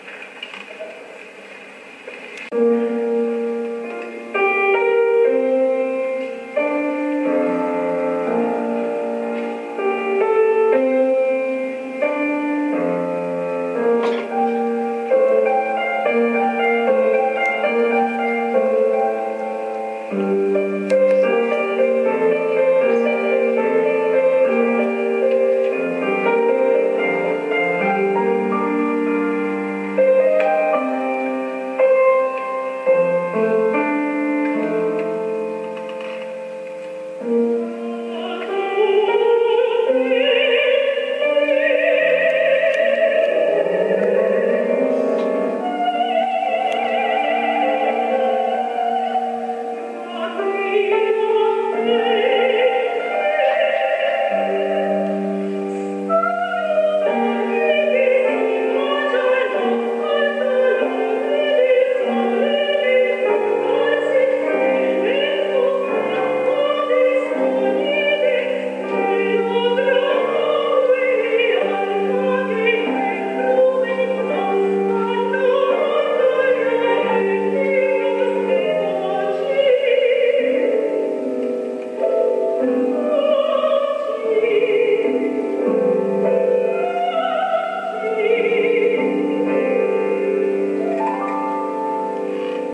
Chiesa di S.Agostino
al Concerto vocale strumentale con musica originale di Euro Teodori